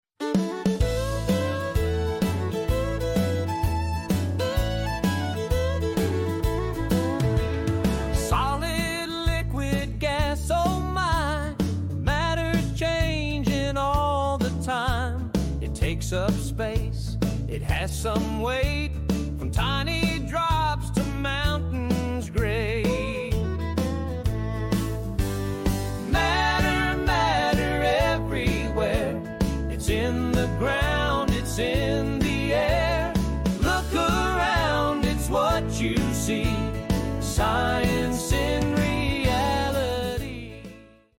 Giddy up and learn about solids, liquids, and gases on the farm! From steamy tea kettles to muddy boots, this country jam makes science a rootin’-tootin’ good time!